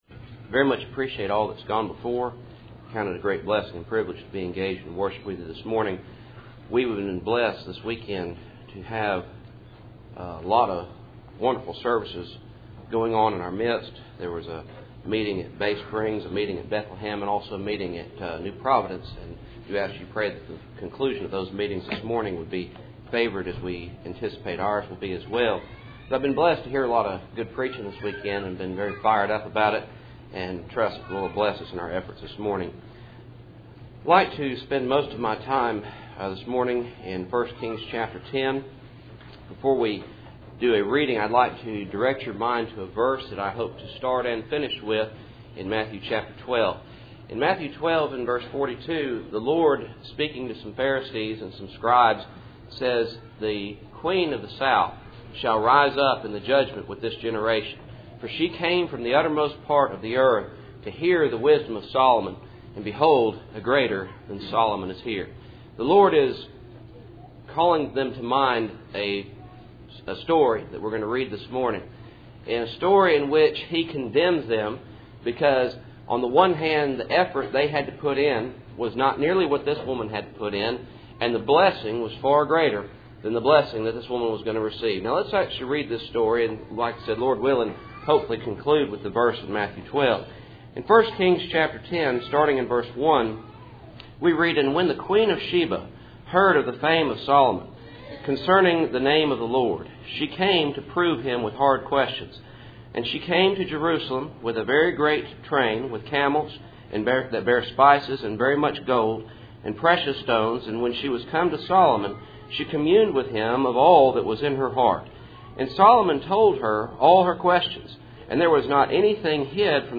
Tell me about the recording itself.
Cool Springs PBC Sunday Morning %todo_render% « Contentment